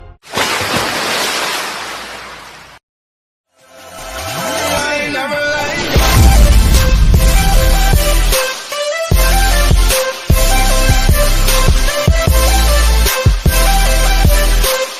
Shower Running
Shower Running is a free ambient sound effect available for download in MP3 format.
# shower # water # bathroom About this sound Shower Running is a free ambient sound effect available for download in MP3 format.
386_shower_running.mp3